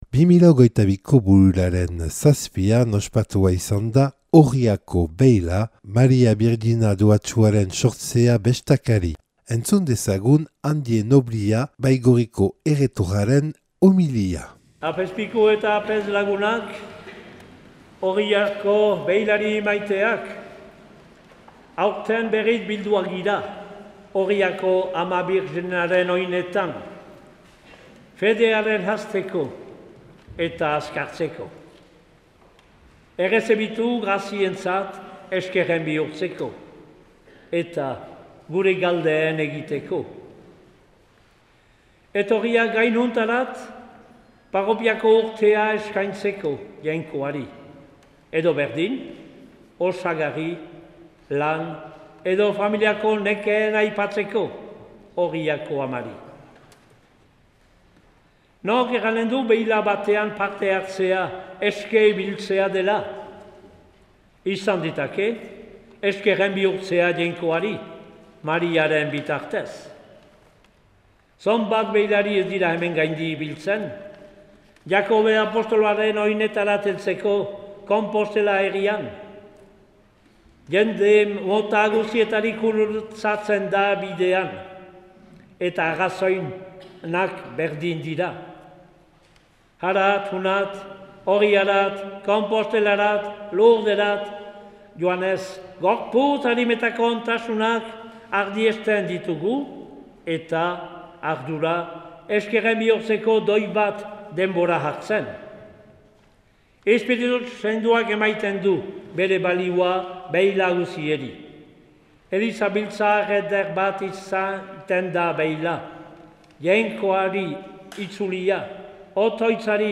Elkarrizketak eta erreportaiak